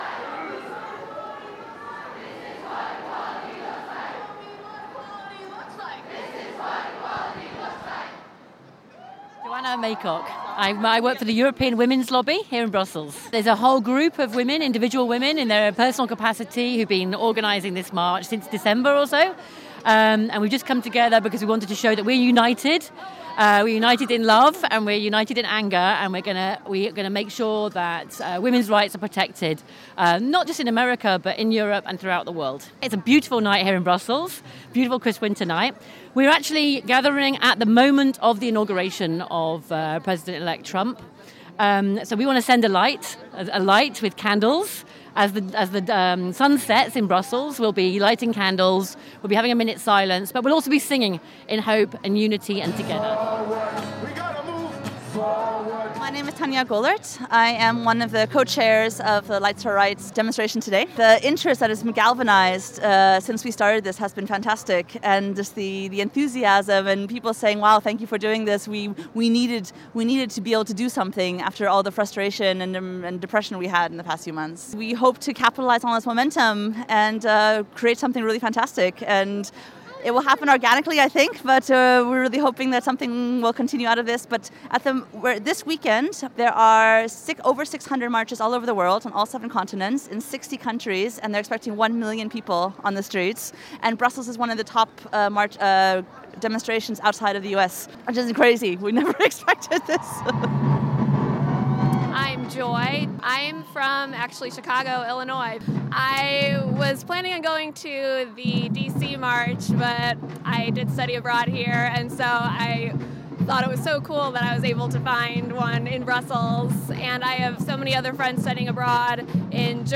Lights for Rights demonstration in Brussels
More than 600 people gathered at the Place de la Monnaie in Brussels at sundown on Friday to stand up for the rights of people they say are threatened by a Trump presidency. Timed to coincide with Donald Trump’s inauguration, the Lights for Rights demo featured speeches, music, the lighting of candles and a minute’s silence and was one of 600 organised in 60 countries around the world this weekend.